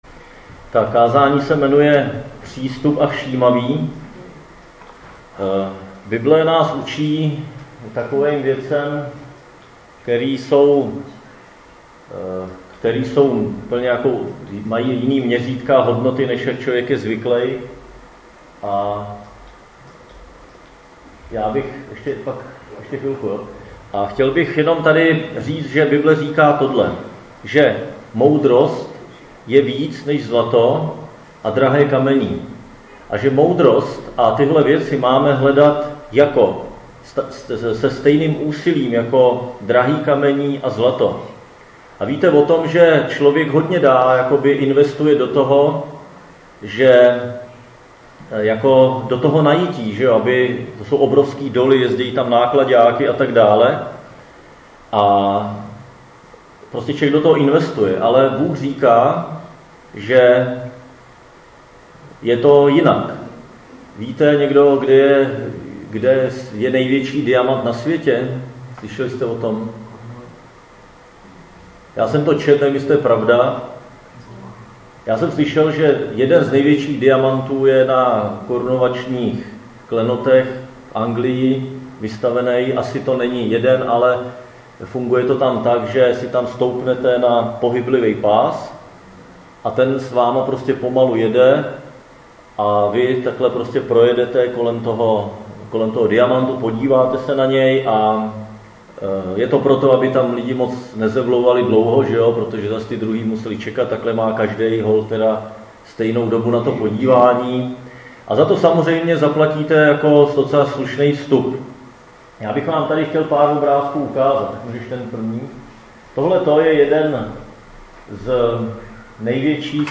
Křesťanské společenství Jičín - Kázání 19.2.2017